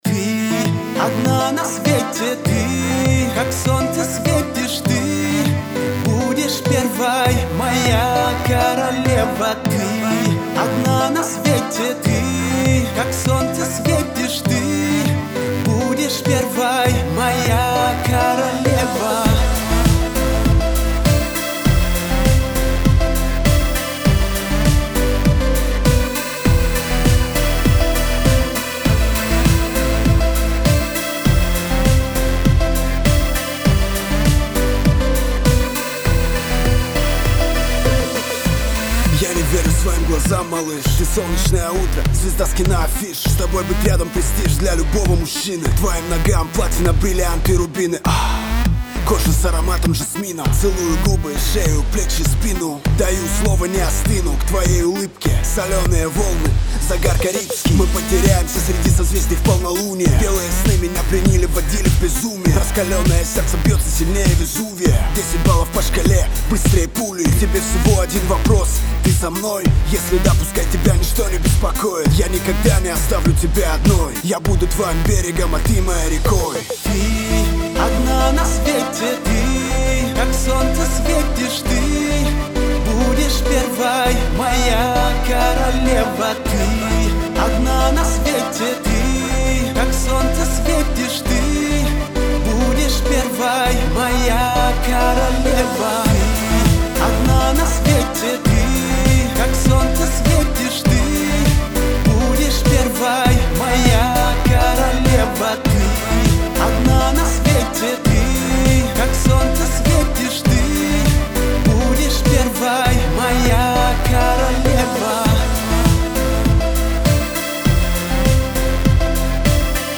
Категория: Русский рэп 2016